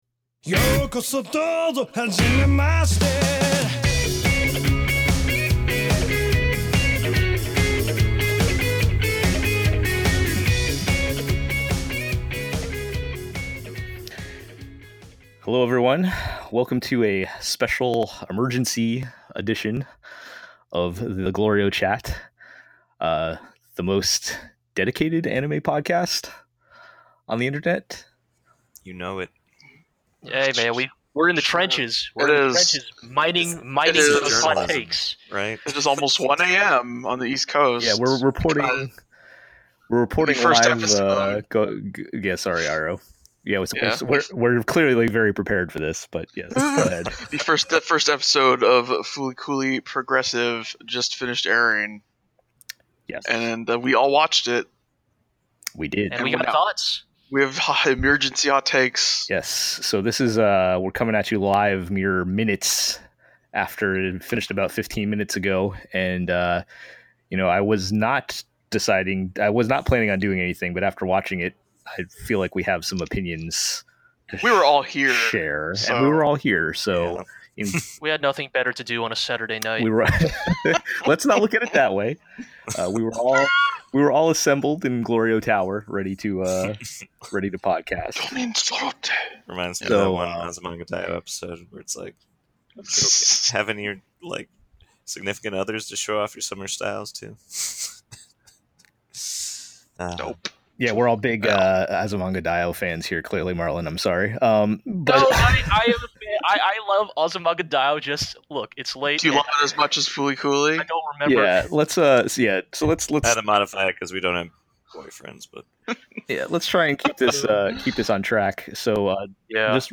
Mere minutes after the airing of FLCL Progressive episode 1, Team GLORIO USA got on their microphones to record some hot takes.